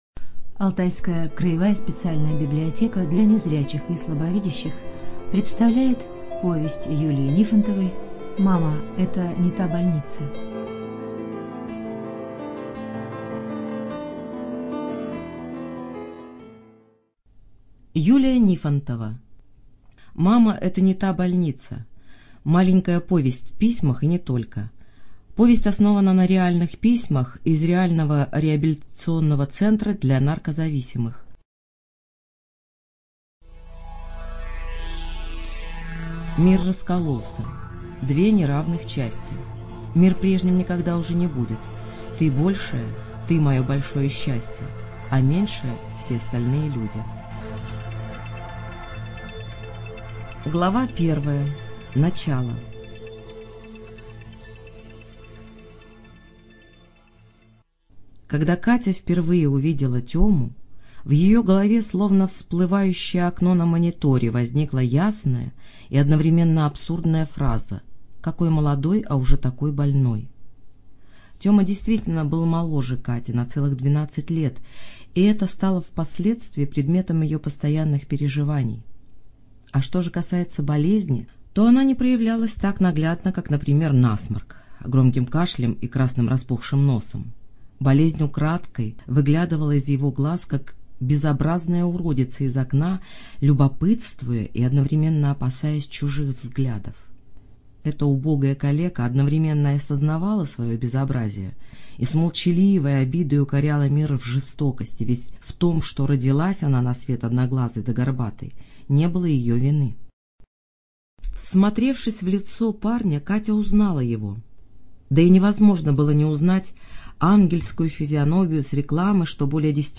ЖанрСовременная проза
Студия звукозаписиАлтайская краевая библиотека для незрячих и слабовидящих